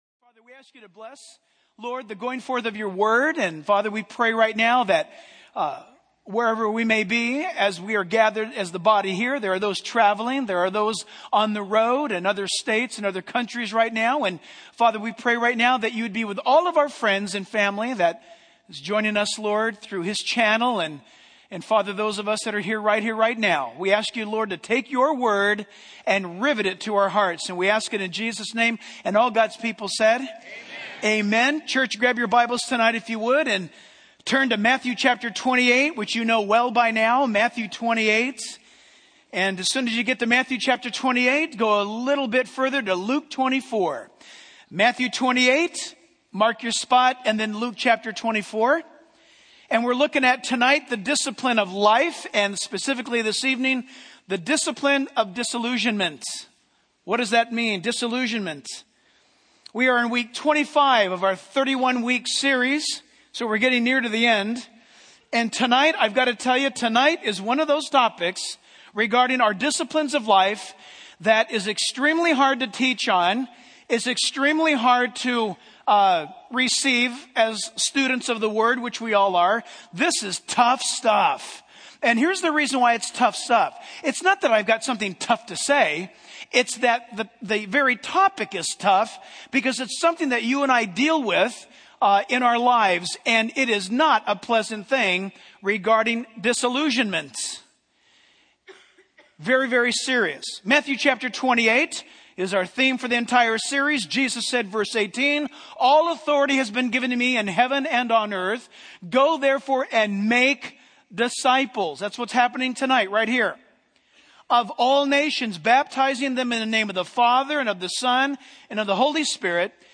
In this sermon, the pastor emphasizes the importance of following Jesus and keeping our eyes on Him.